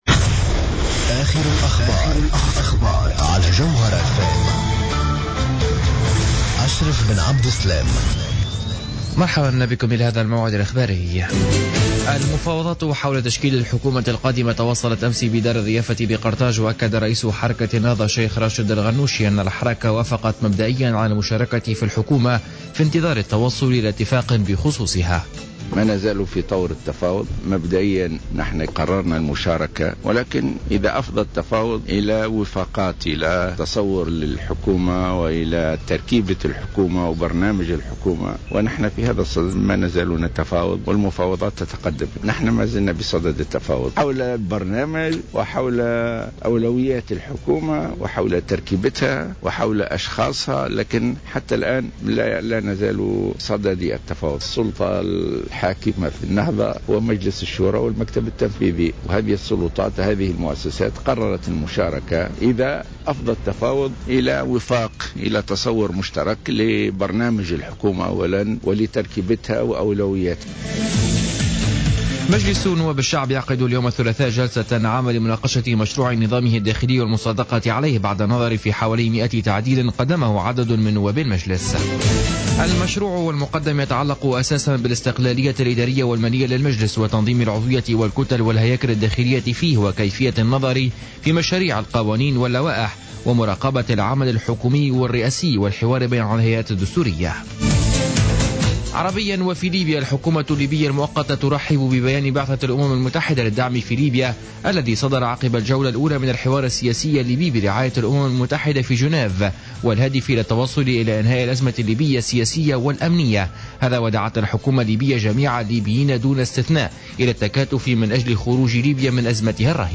نشرة أخبار منتصف الليل ليوم الثلاثاء 20-01-15